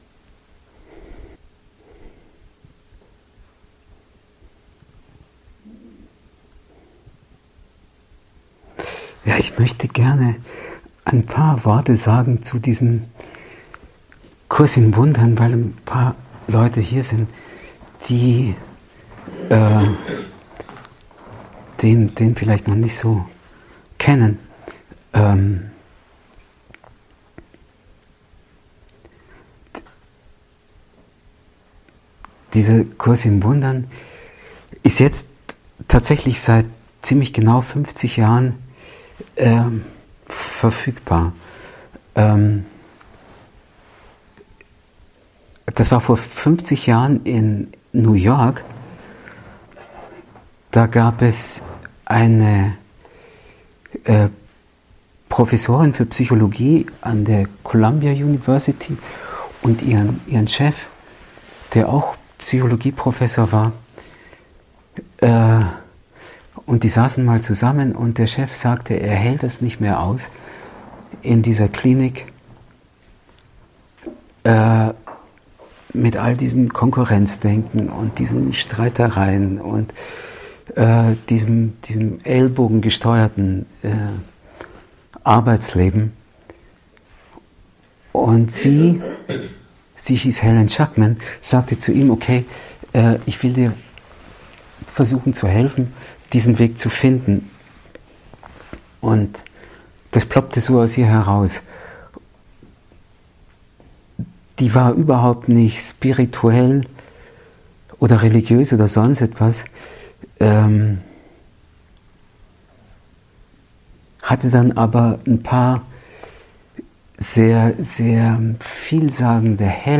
Ausschnitt eines Treffens vom 7. Februar 2016